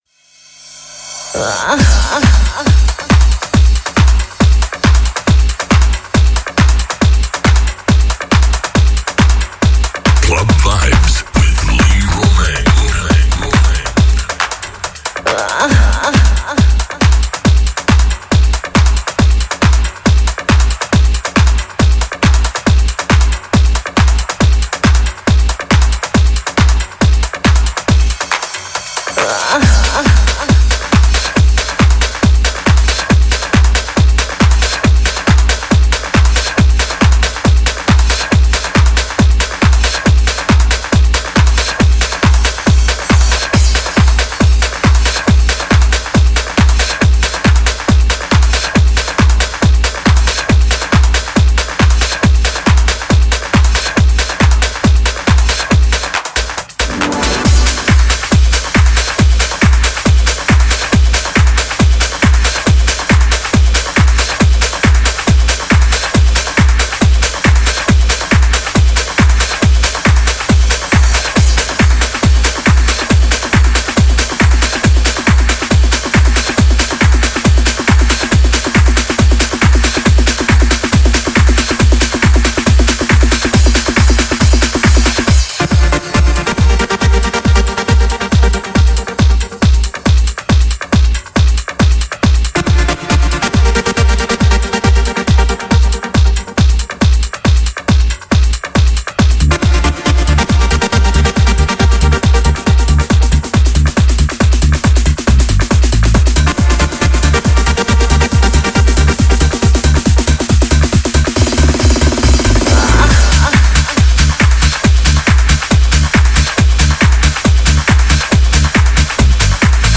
60 min mix